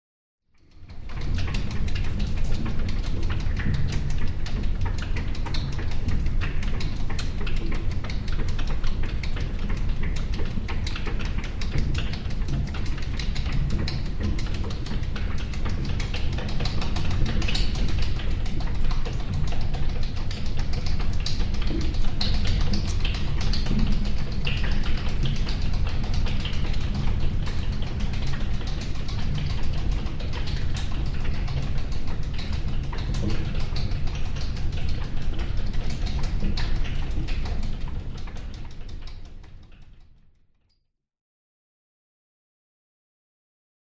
На этой странице собраны звуки шестерёнок разного типа: от мягкого перекатывания до резкого металлического скрежета.
Шестерня медленно вращается в просторном складе